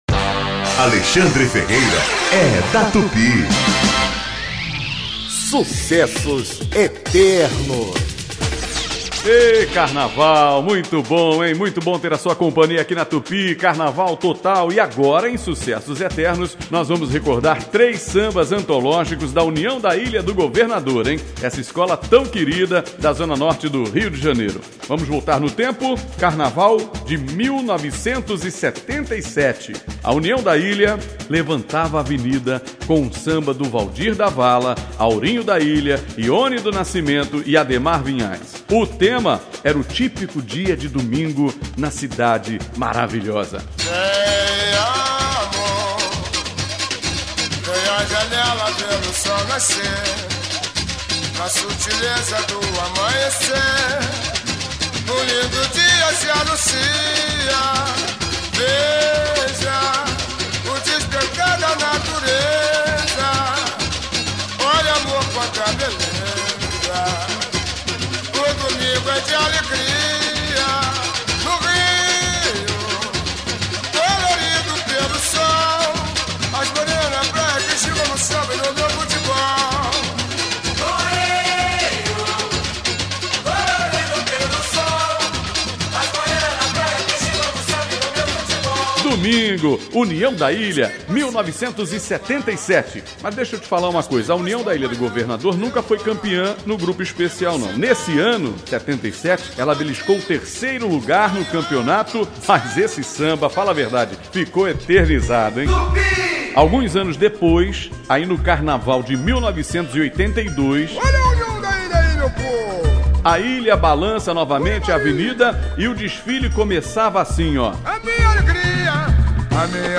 O programa vai ao ar de segunda a sábado, a partir de meia-noite, com muita interação, bate-papo, informação e boa música.